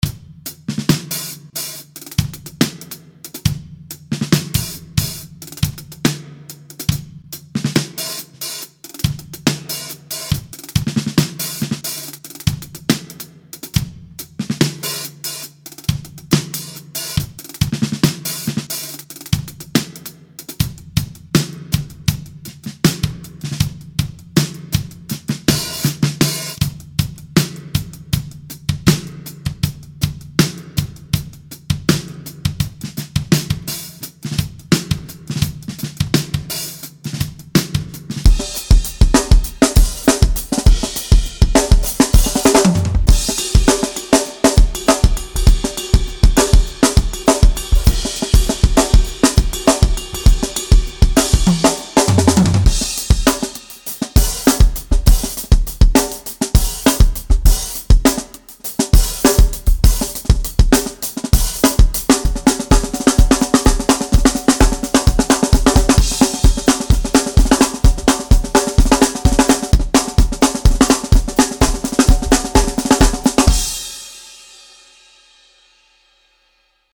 Crisp and tasty beats with a dusty, lo-fi, boom bap vibe.